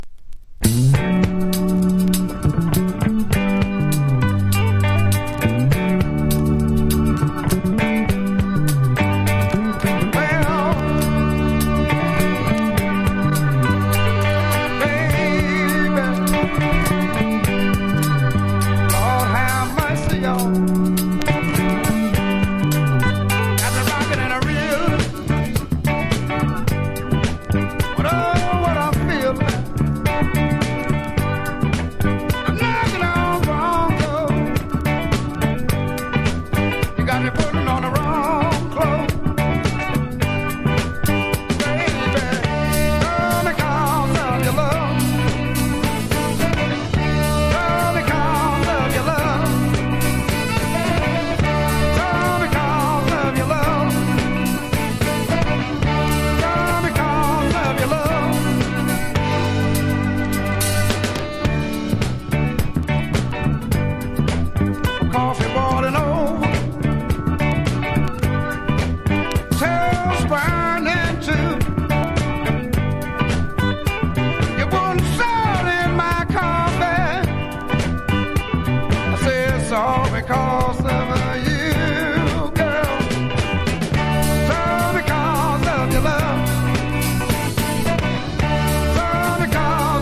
シカゴ・ブルース好盤！！
RHYTHM & BLUES